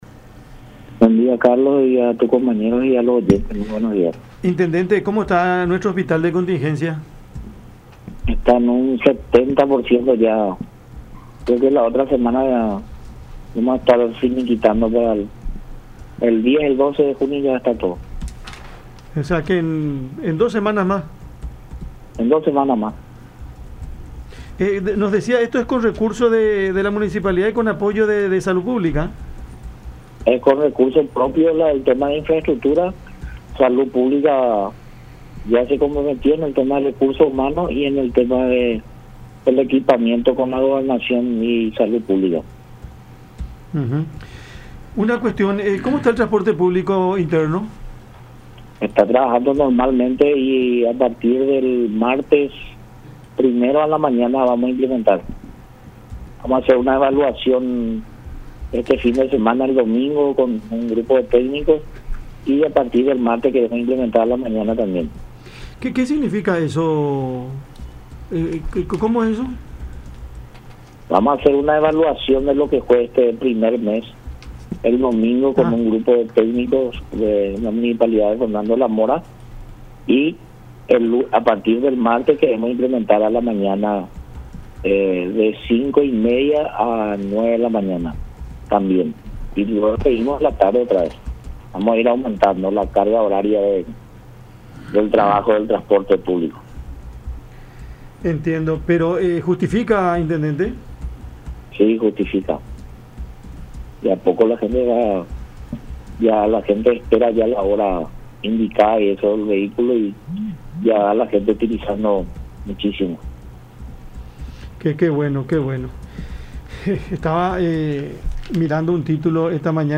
“Desde el martes que viene, queremos implementar el uso de buses internos por la mañana. Queremos que el horario en el que funcionen estos buses internos sea desde las 05:30 de la mañana hasta las 09:00”, expuso Riveros en conversación con Cada Mañana por La Unión.